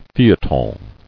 [feuil·le·ton]